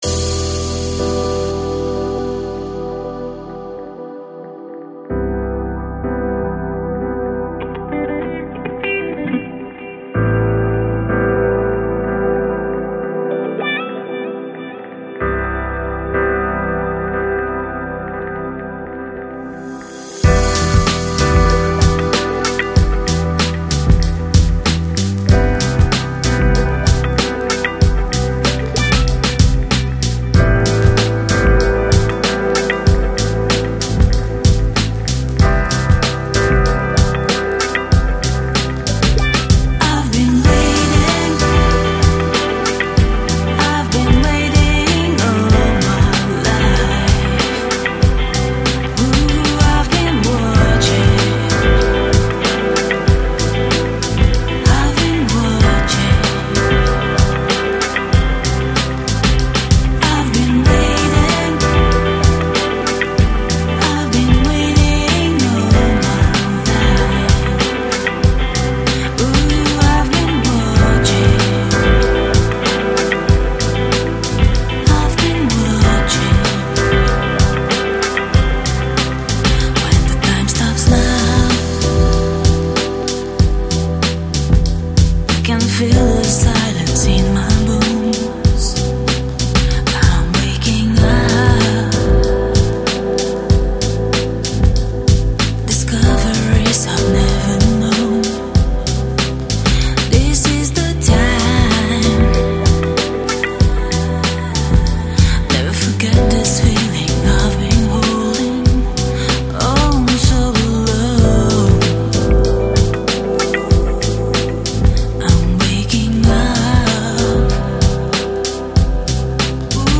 Downtempo, Lounge, Chillout Год издания